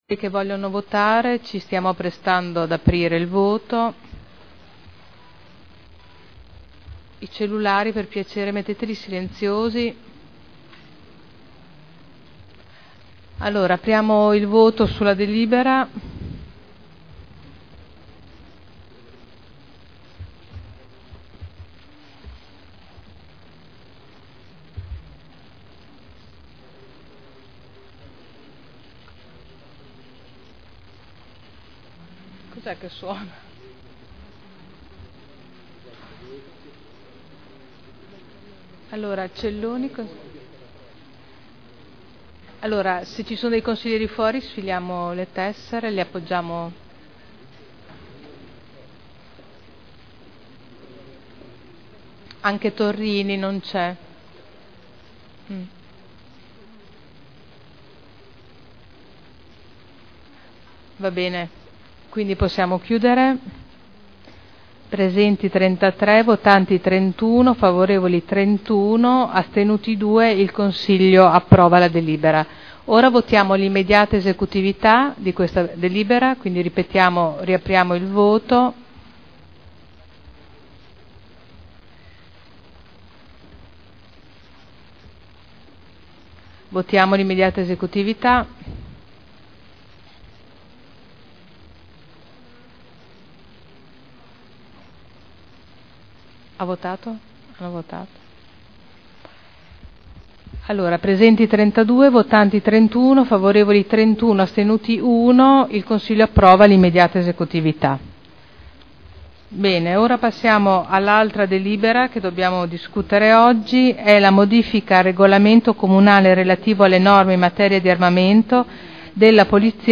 Seduta del 05/12/2011. Il Presidente Caterina Liotti mette ai voti.